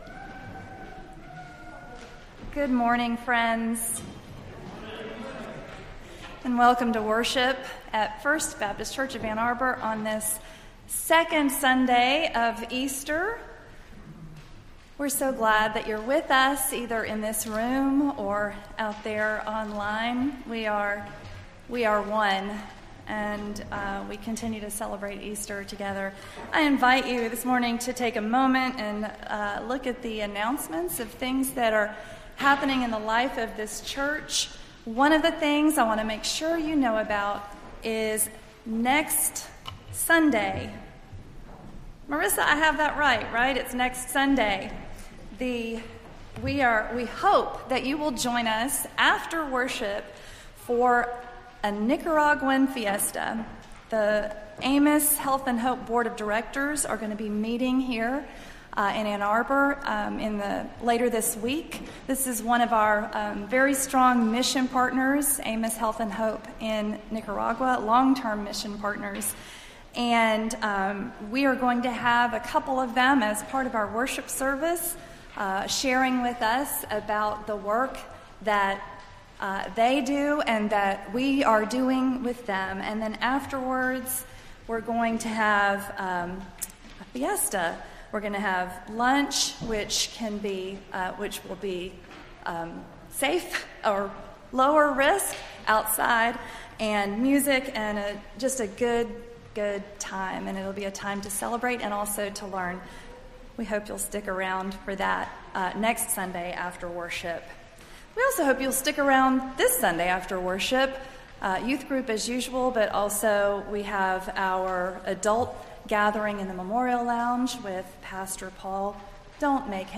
Entire April 24th Service